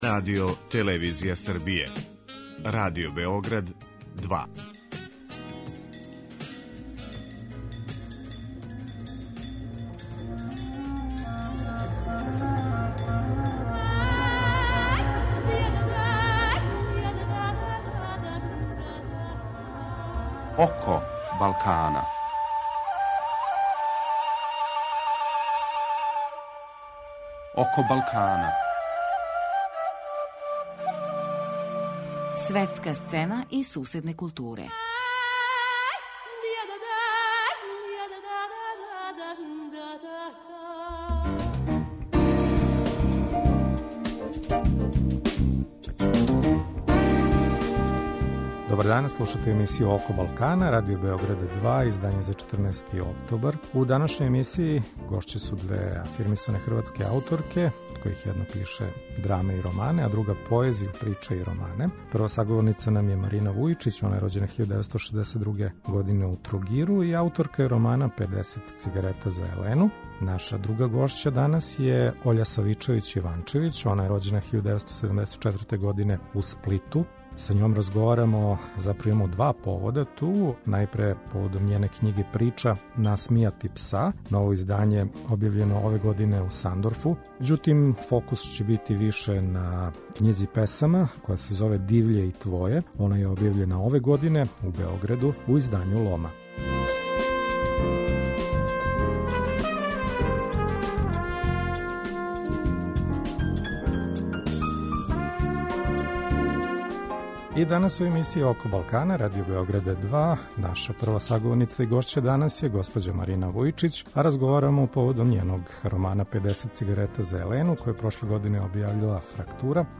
У данашњој емисији гошће су две афирмисане хрватске ауторке, од којих једна пише драме и романе а друга поезију, приче и романе.